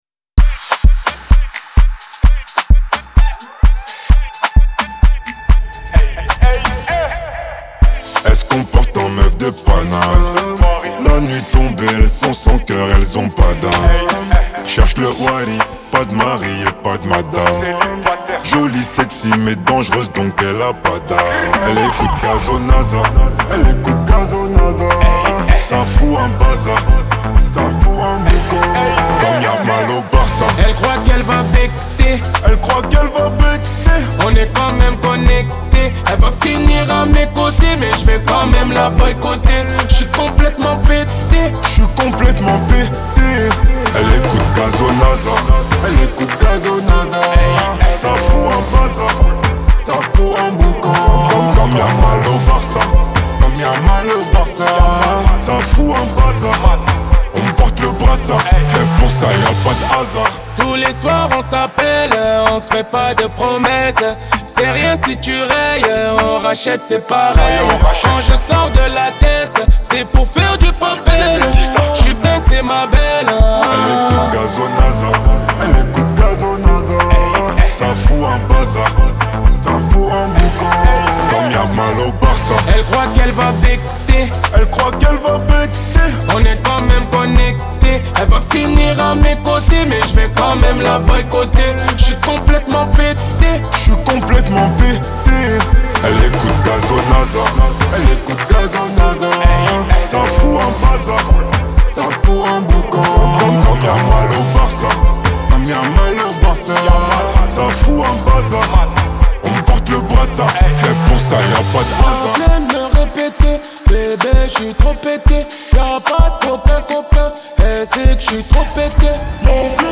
Genres : pop urbaine, french rap, french r&b